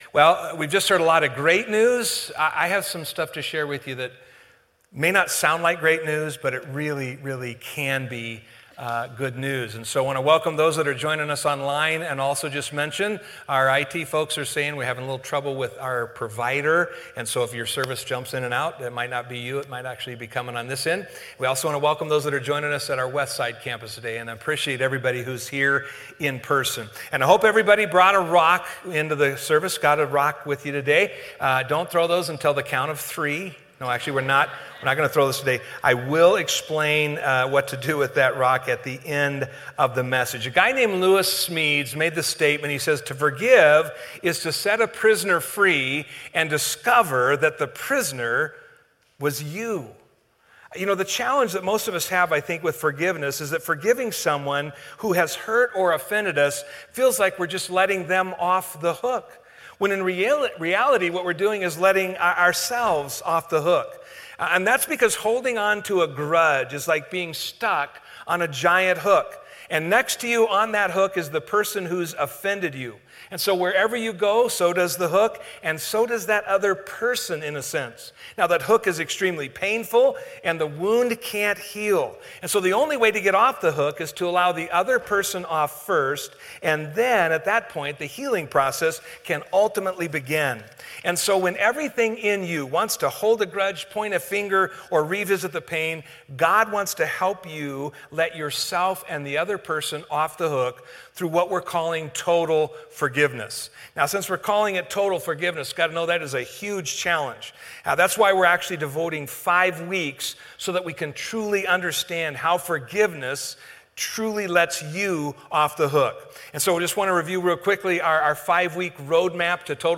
A message from the series "Off the Hook."